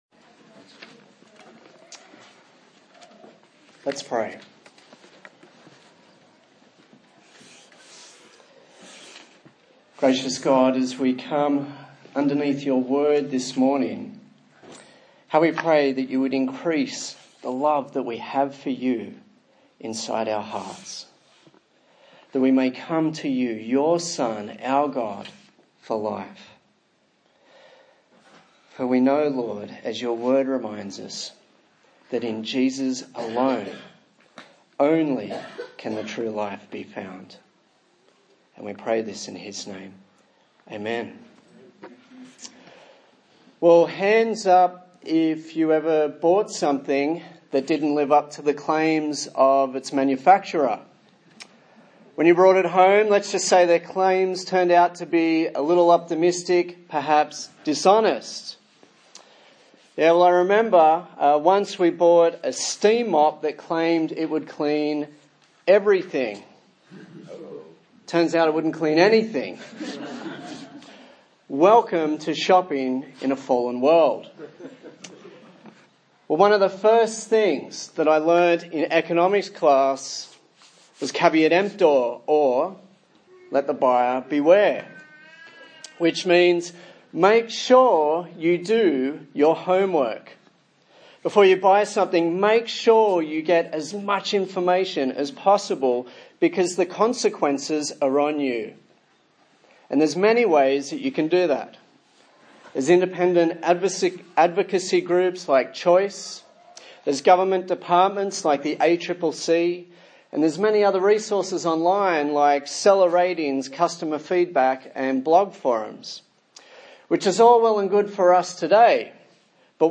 John Passage: John 5:31-47 Service Type: Sunday Morning